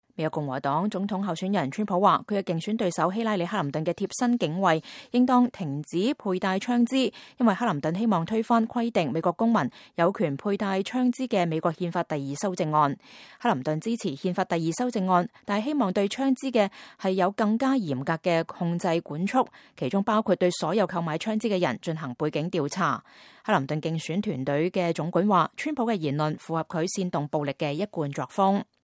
川普星期五在邁阿密舉行的一次集會上說，“把他們的槍收走吧。...讓我們看看她會發生什麼事情。...好吧，那會很危險。”